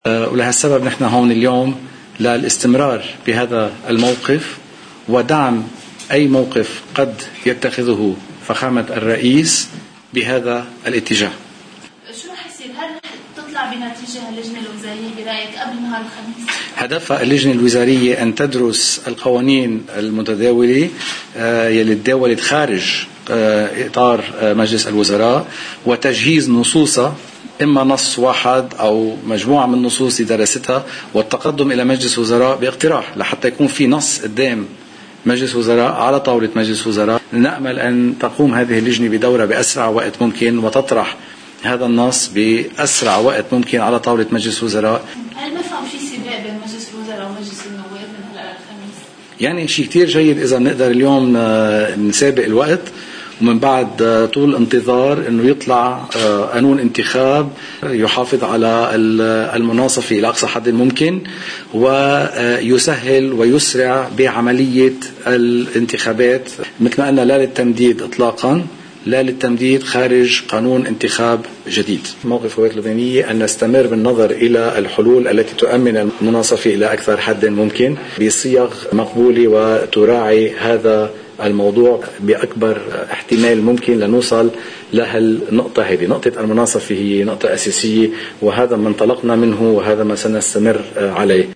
الوزيران حاصباني والرياشي موفدان من جعجع التقيا الرئيس عون في بعبدا، وقد صرّح بعد اللقاء حاصباني:
hasbani-baabda.mp3